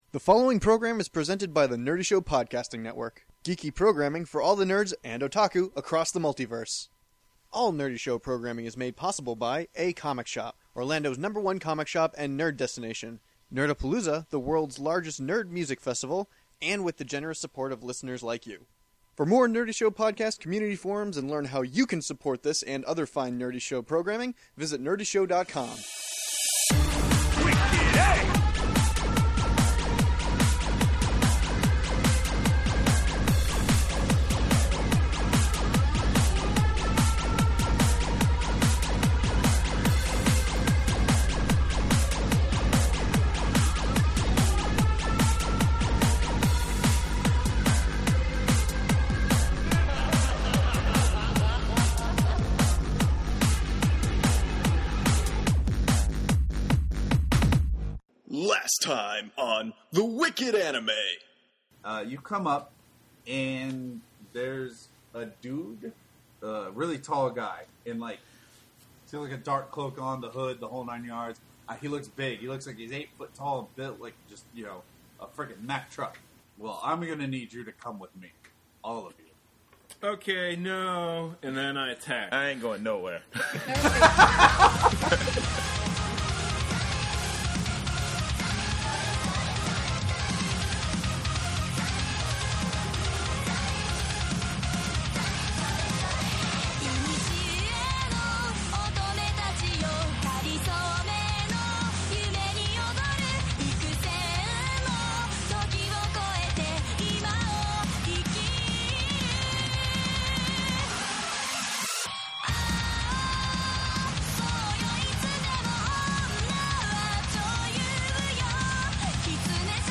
Wicked Anime is playing the anime table top RPG BESM aka Big Eyes Small Mouth.